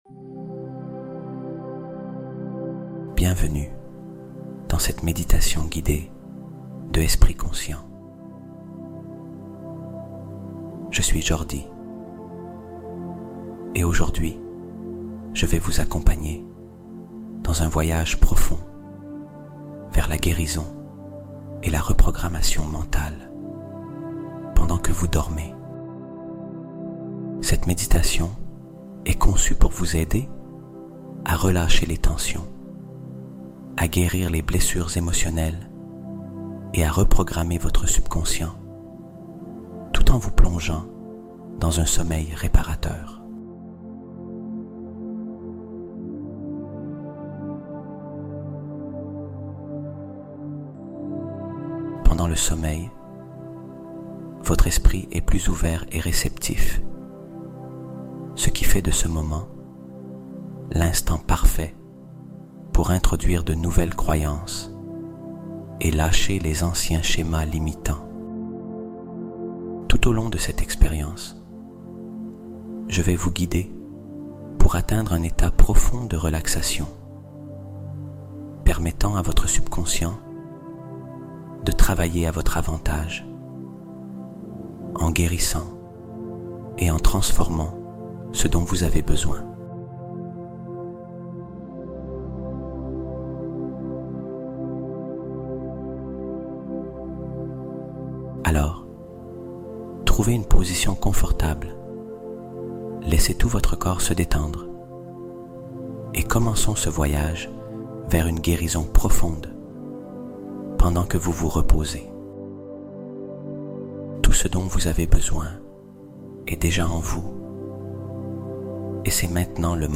Deviens Mentalement INVINCIBLE en Dormant : Hypnose de Reprogrammation Profonde (Sans Interruption)